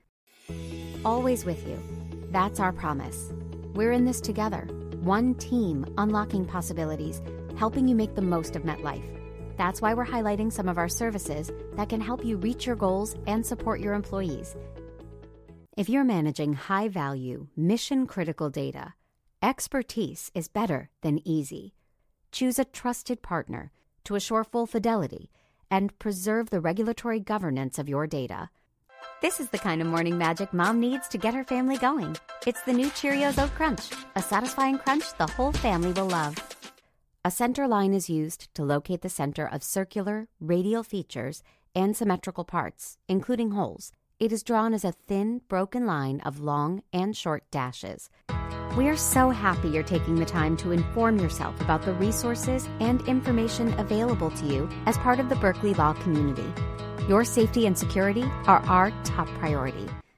Voice Gender: Female